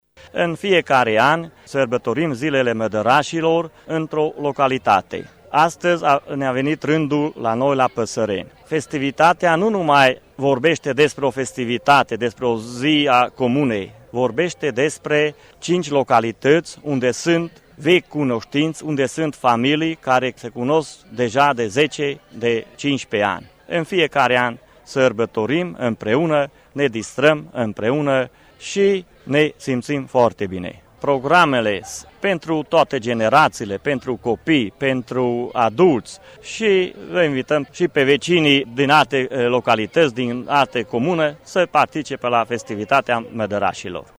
Primarul comunei Păsăreni, Szőcs Antal: